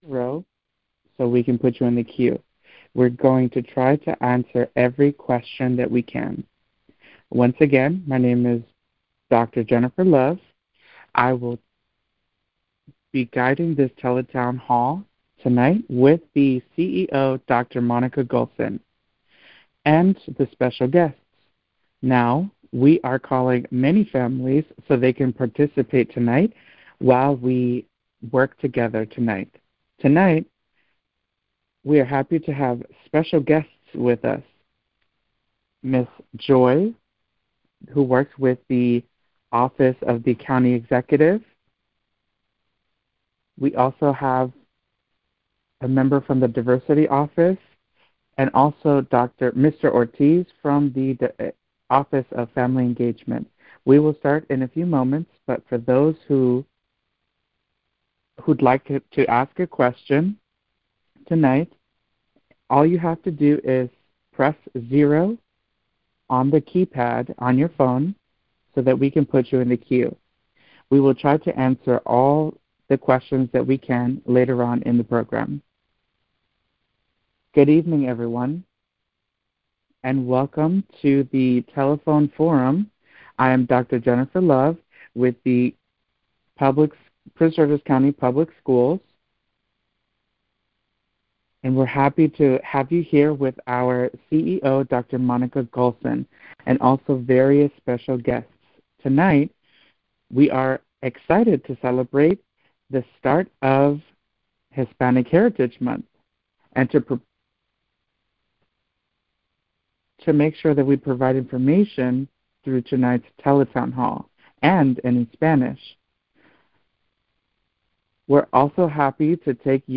Hispanic Heritage Month teletown hall in English.mp3
hispanic-heritage-month-teletown-hall-in-english.mp3